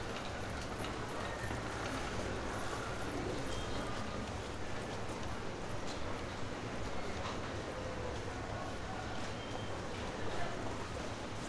Paris_street2.R.wav